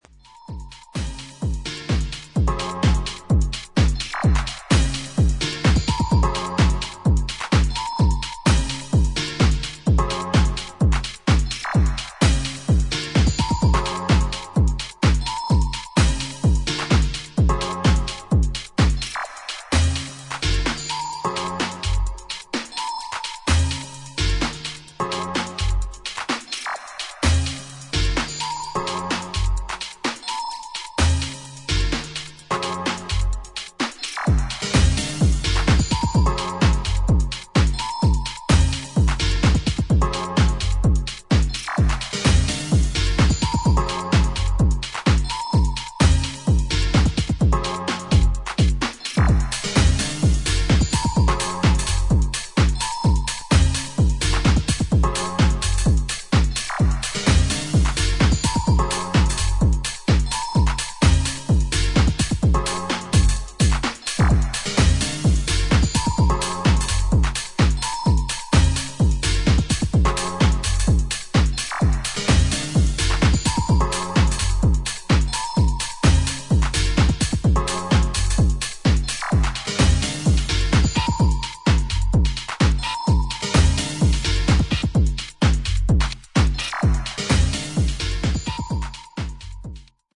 新旧どのテクノ・ハウスサウンドにも対応するであろう、円熟味を帯びた一枚です。